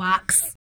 02 RSS-VOX.wav